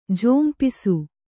♪ jhompisu